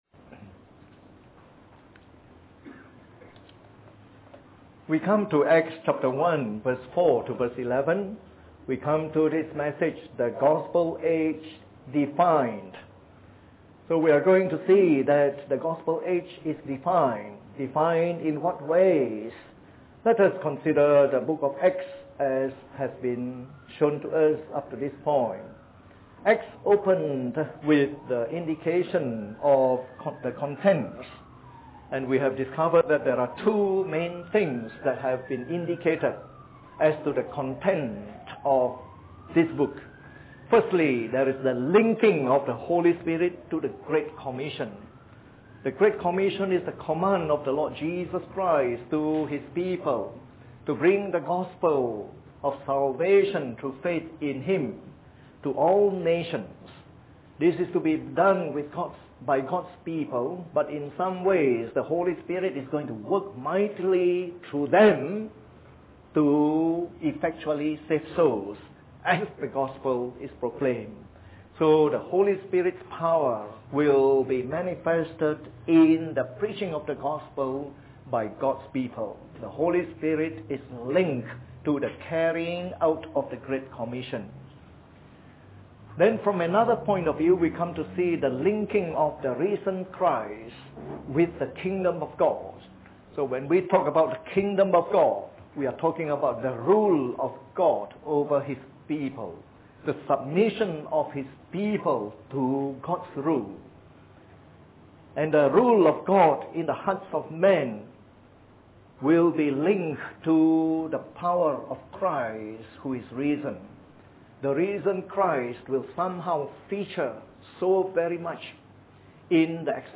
Part of our new series on “The Acts of the Apostles” delivered in the Evening Service.